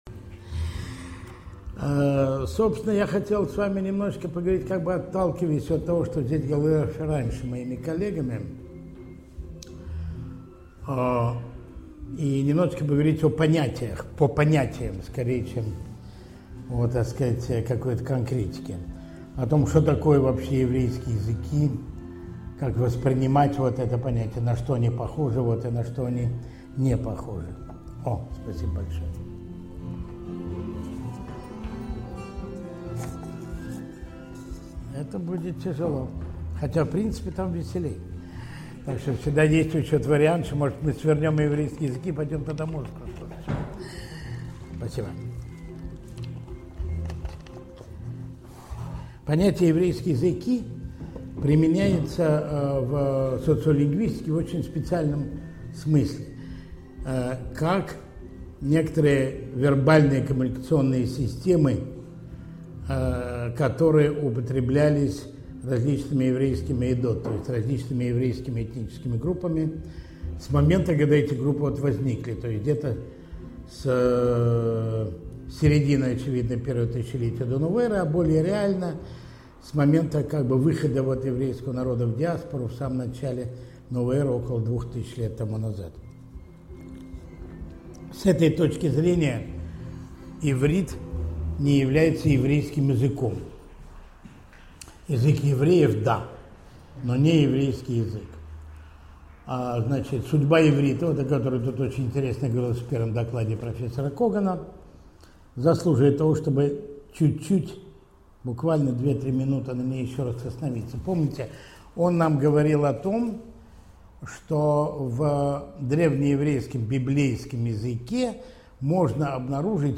Аудиокнига Еврейские языки на карте мира | Библиотека аудиокниг